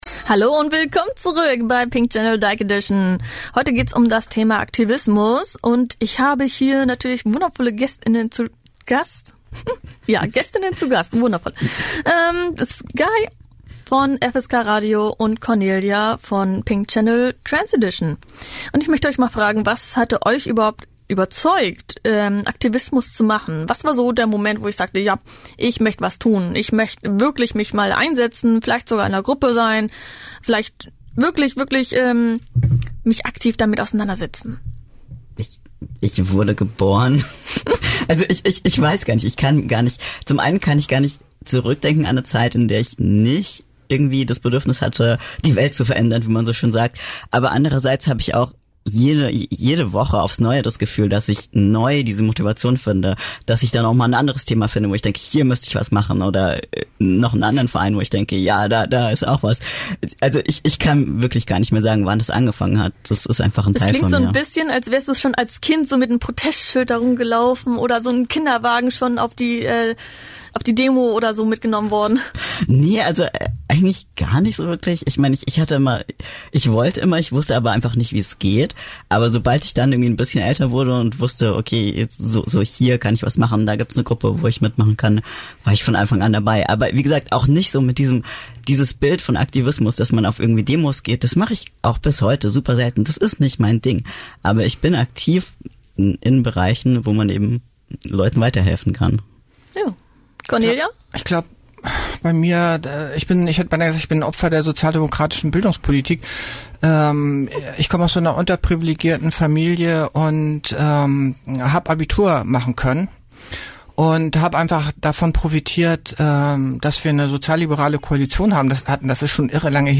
Die Gäste erzählen, was sie motiviert hat aktiv zu werden.